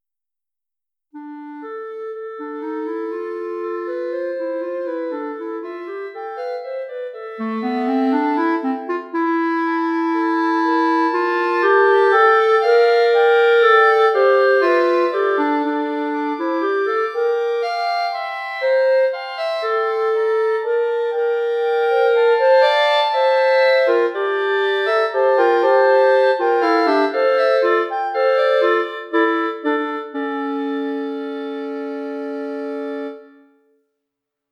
3rd part loud, mm. 48-63